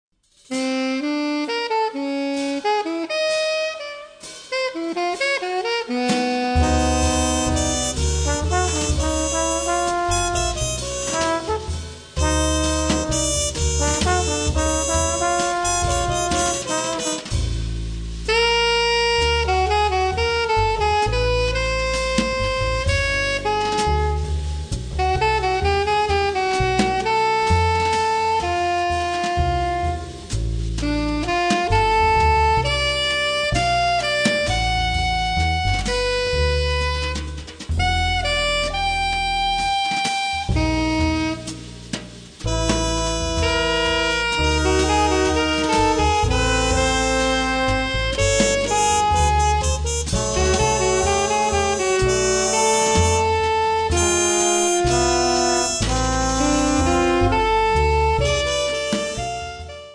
drum
double bass
alto sax and clarinet
trumphet, flugelhorn
trombone